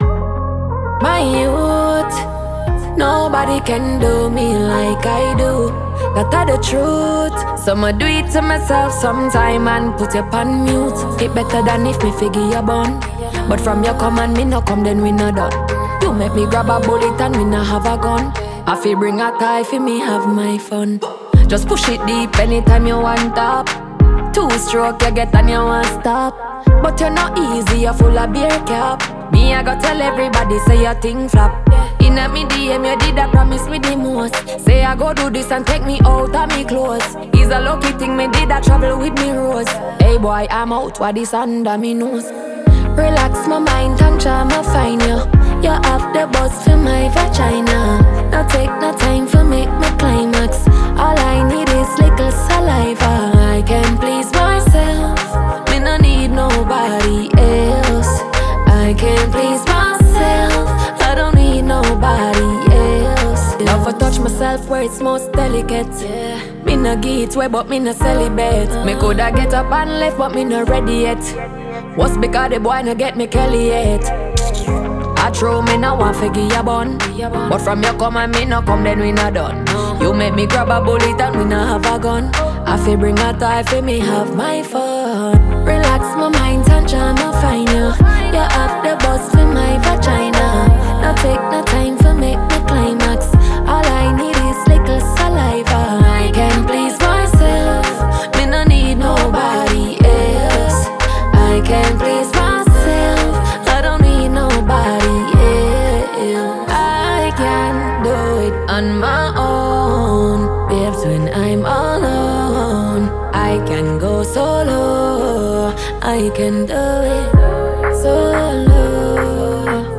Dancehall